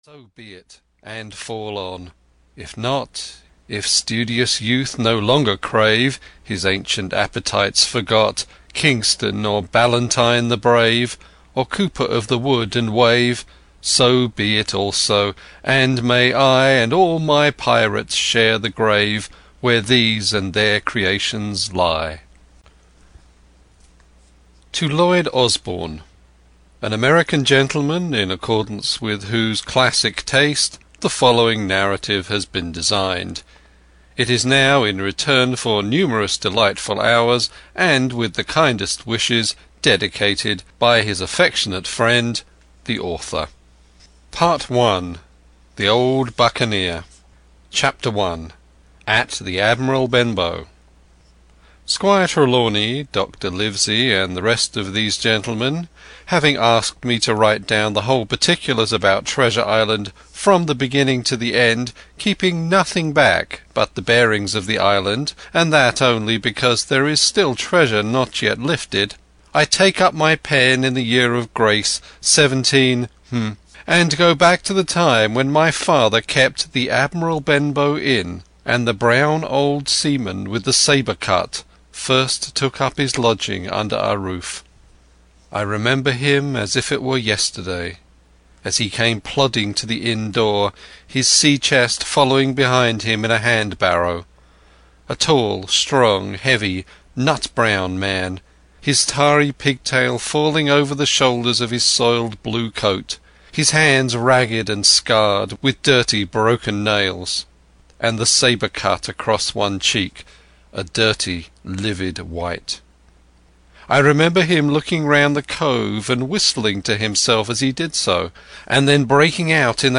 Treasure Island (EN) audiokniha
Ukázka z knihy